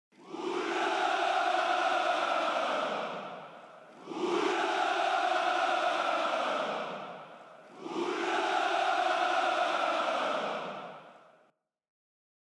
На этой странице собраны уникальные звуки Парада Победы: марши военных оркестров, рев моторов бронетехники, аплодисменты зрителей.
Крики солдат Ура на Параде Победы 9 мая